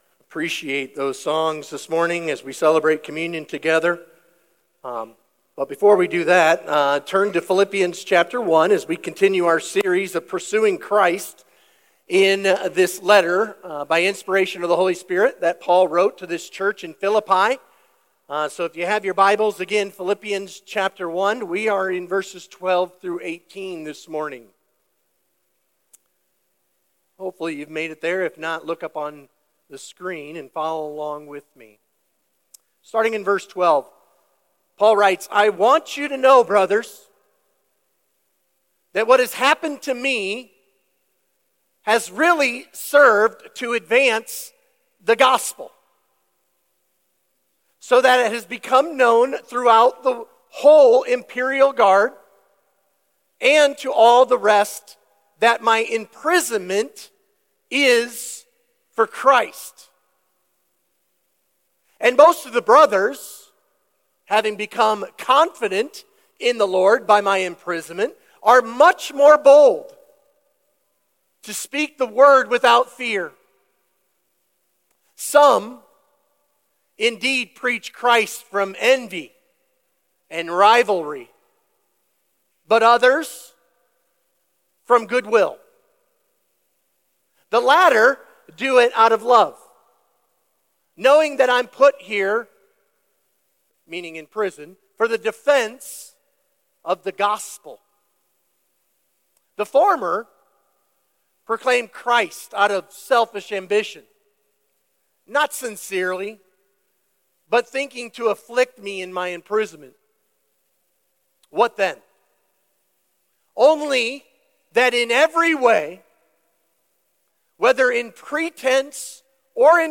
Sermon Questions Based on this Sunday's passage, How do you think Paul decided if an event/circumstance was good or bad?